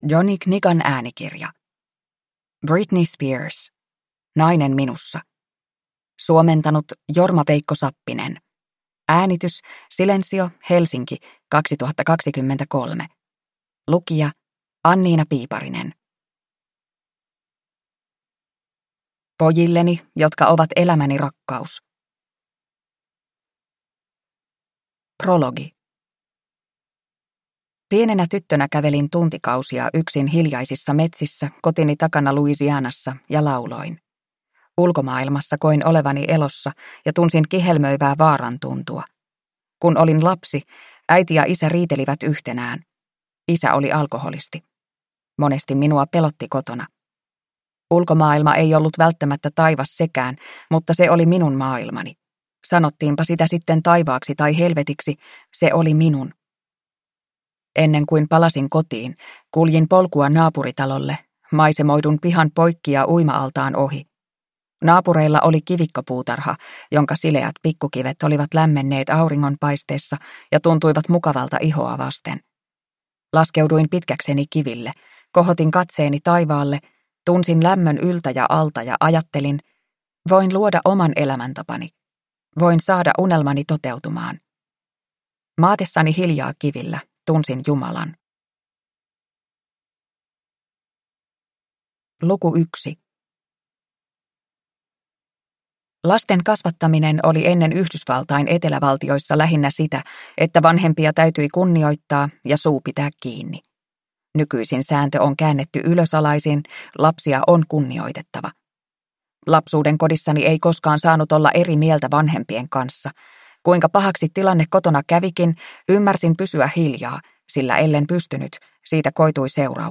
Nainen minussa – Ljudbok – Laddas ner